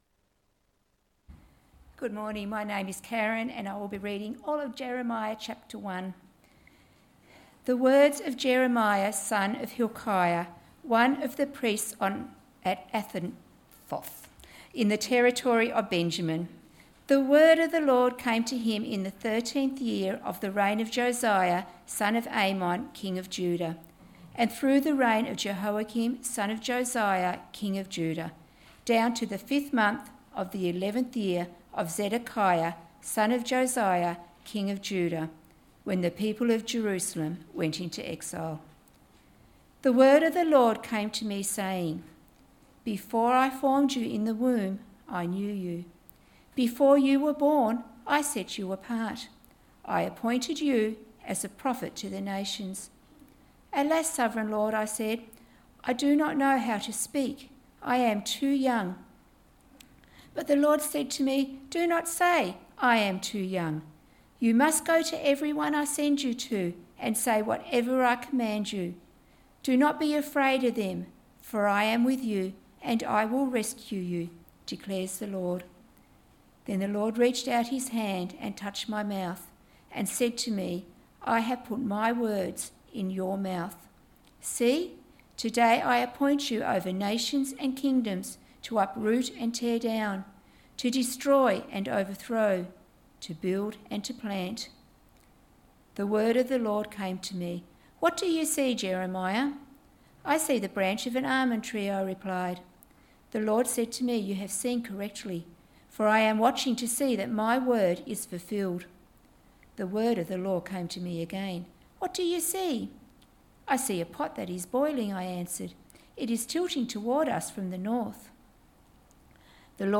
Sermon Series: The Prophets